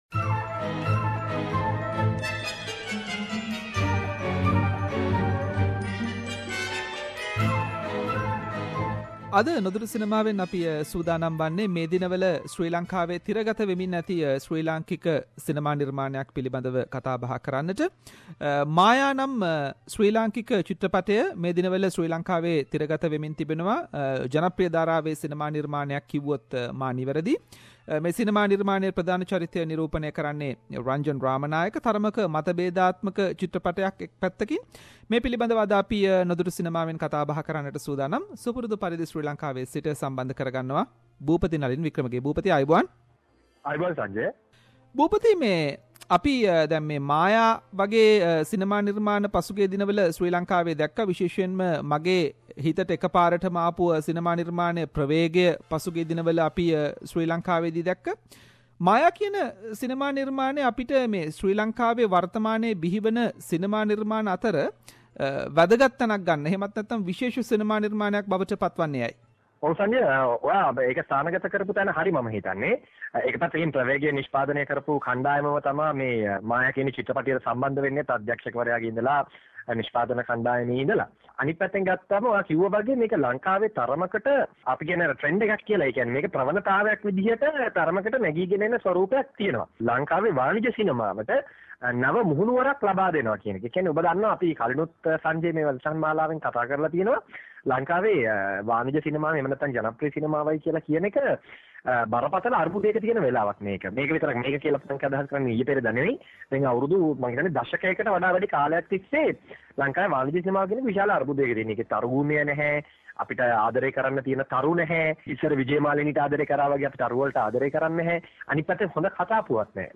In this month's SBS Sinhalese cinema segment we have analyzed Sri Lankan film Maya. Cinema critic